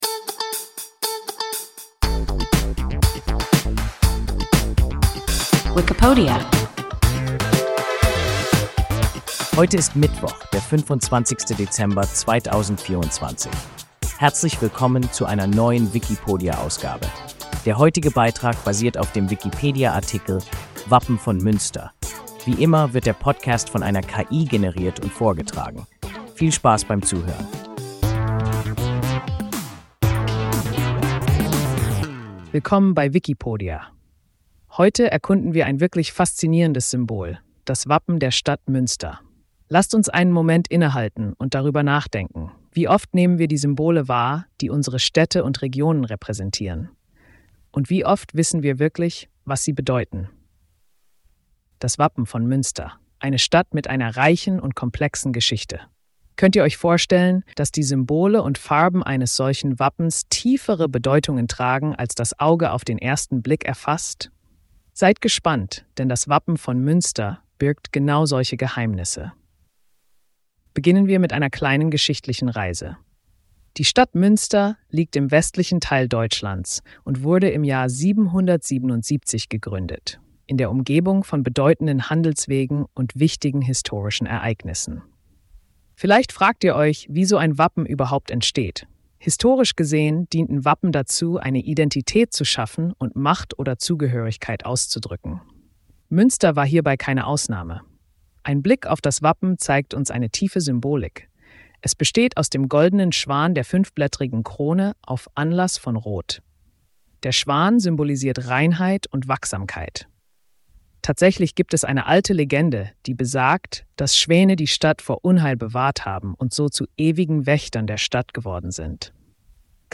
Wappen von Münster – WIKIPODIA – ein KI Podcast